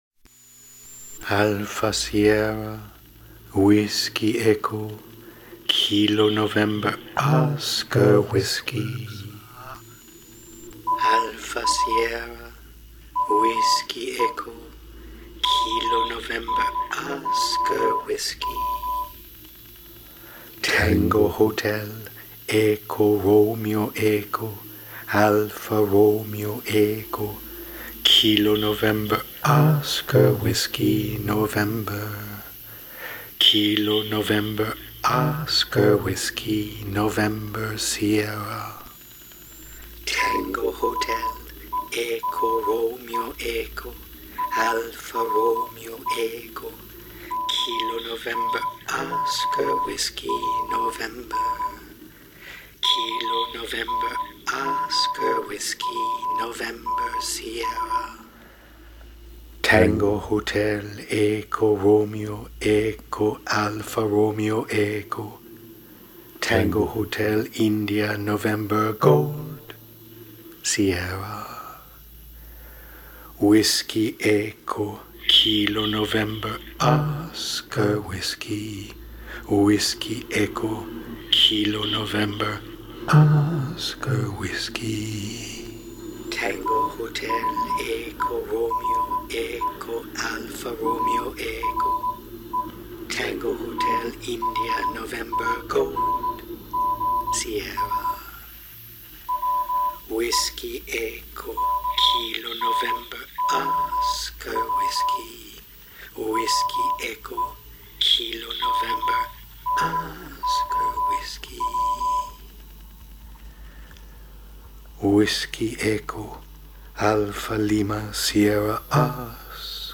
Coded Radiosong based on a remark by Donald Rumsfeld.